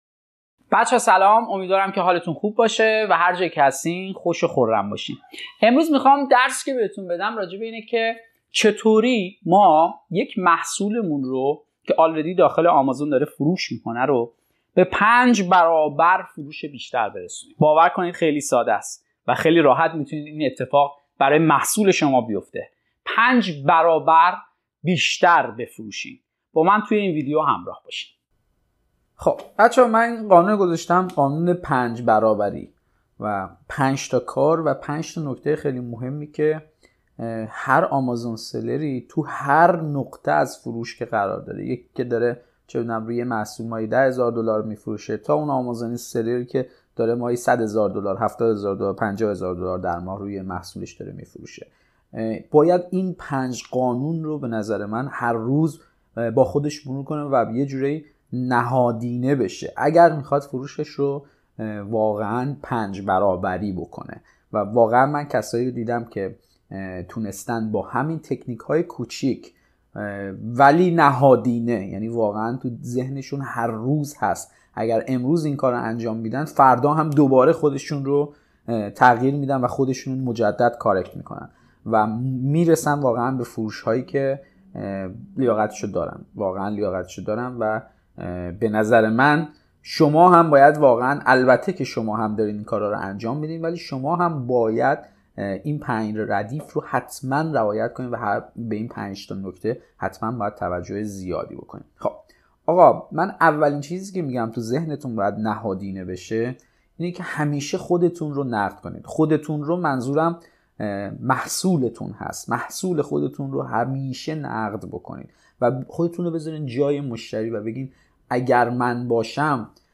این پادکست آموزشی تیم بست سلرس وان هست